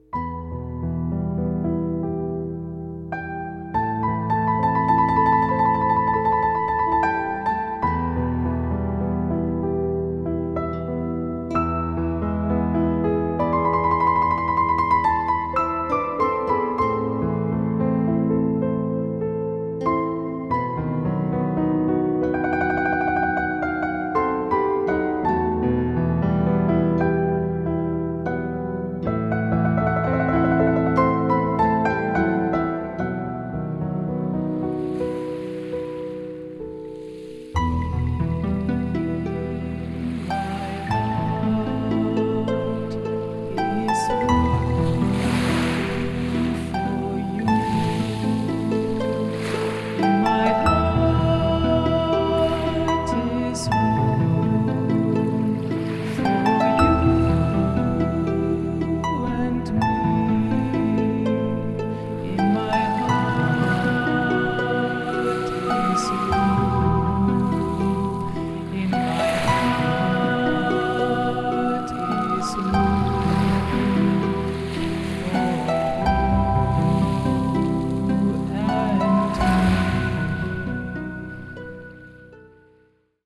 varied, nice melodies and sounds from nature
with sound of a surf, harp and flute